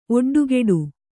♪ oḍḍugeḍu